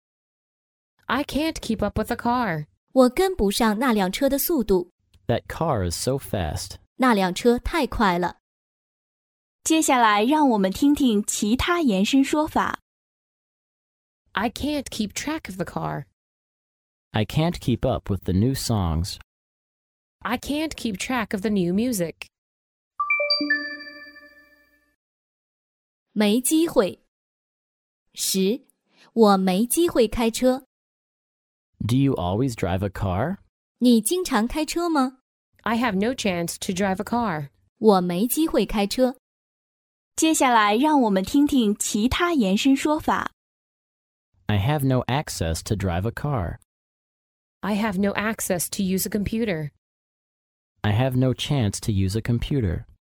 真人发音的朗读版帮助网友熟读熟记，在工作中举一反三，游刃有余。